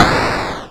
shotgun.wav